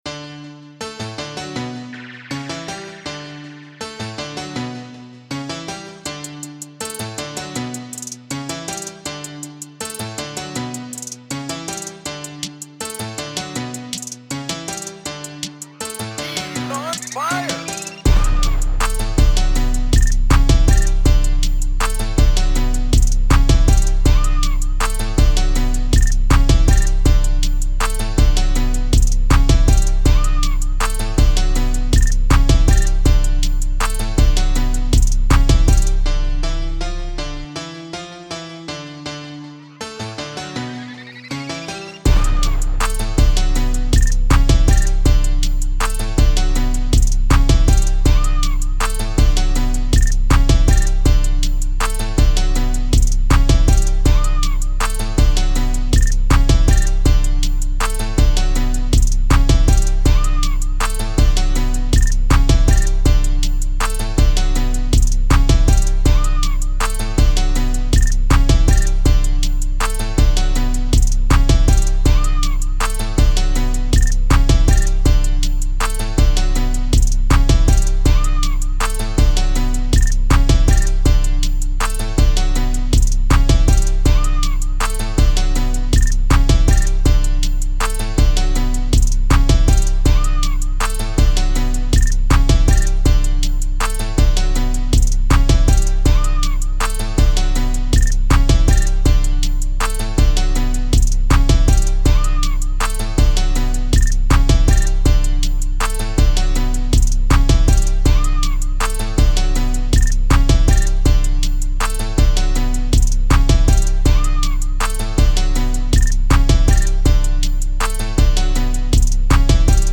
Hip-hop Веселый 160 BPM